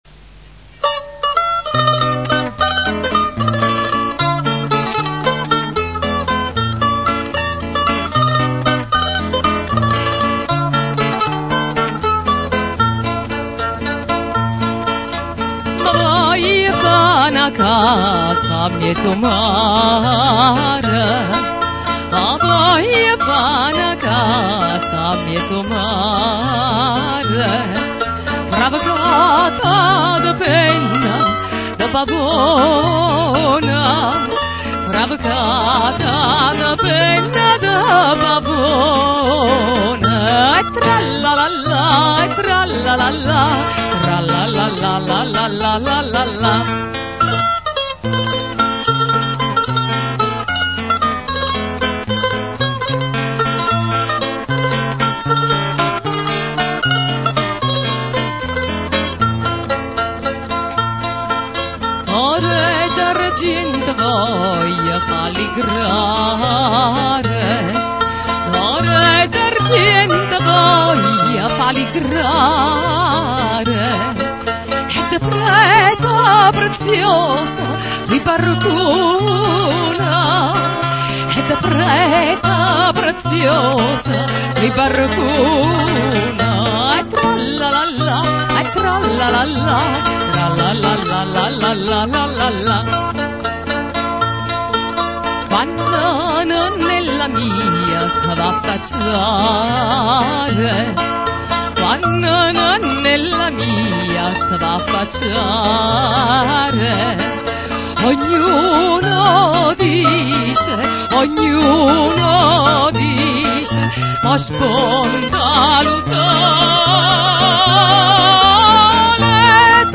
Ascolta Classiche vesuviane cantate da "Napoli Antica" Se non si ascolta subito il suono attendere qualche secondo, solo la prima volta